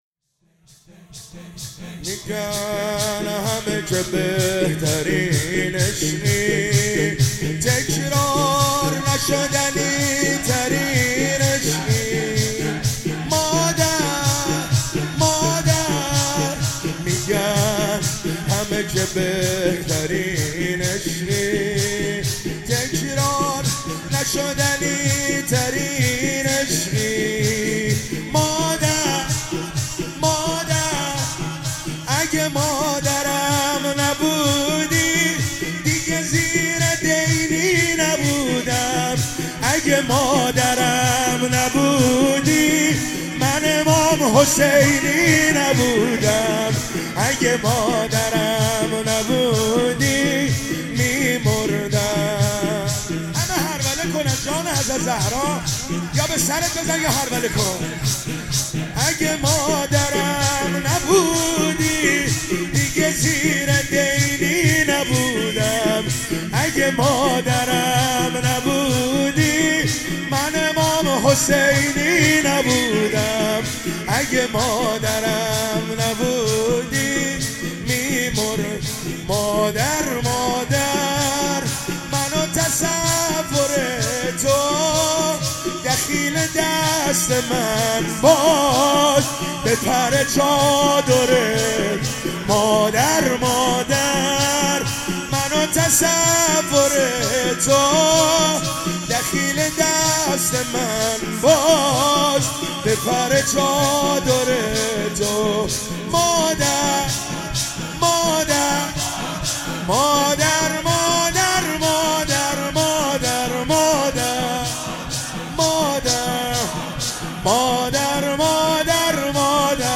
فاطمیه 95